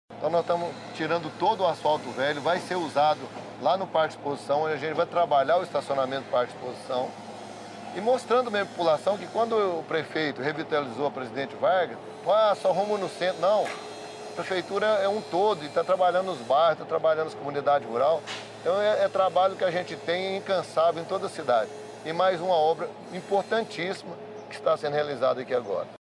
O secretário Municipal de Obras e Infraestrutura José Cornélio de Oliveira também esteve no Santos Dumont ressaltando que a Prefeitura tem feito obras também nos distritos e povoados: